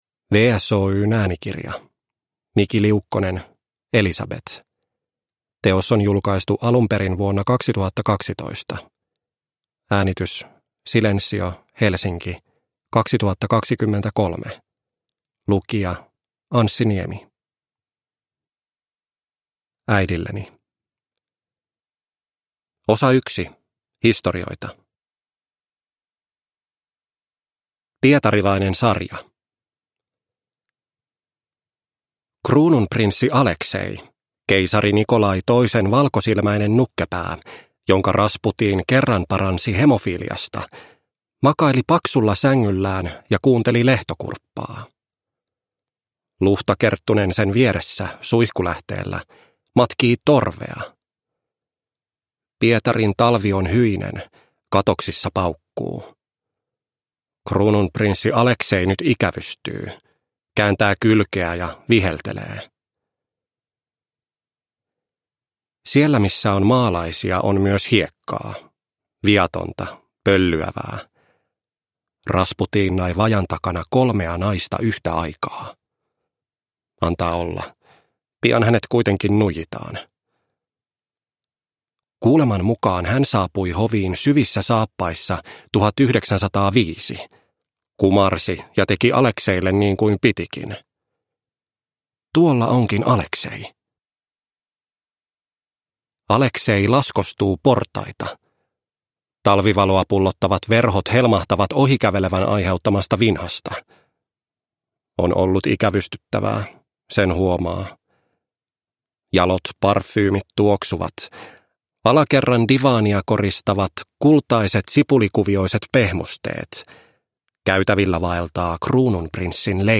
Elisabet – Ljudbok – Laddas ner